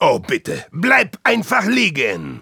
Voice file from Team Fortress 2 German version.
Spy_dominationsniper05_de.wav